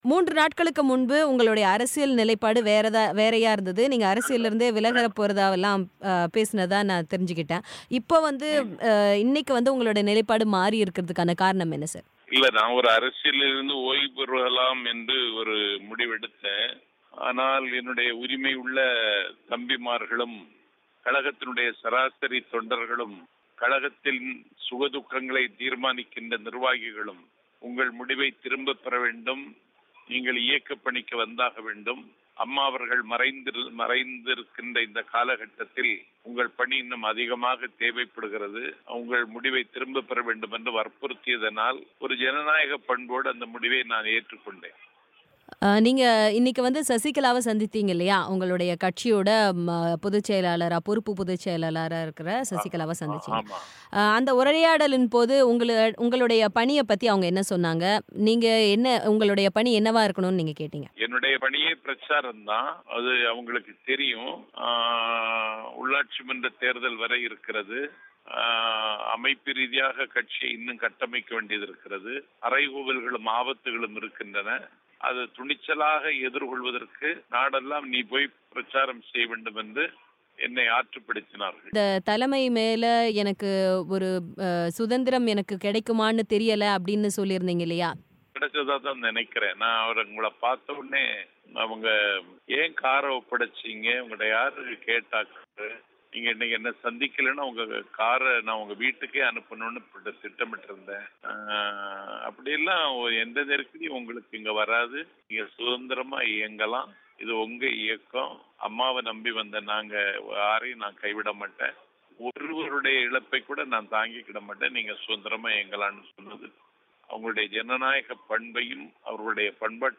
மூன்று நாட்களில் அரசியல் நிலைப்பாட்டை மாற்றியது குறித்து நாஞ்சில் சம்பத் பேட்டி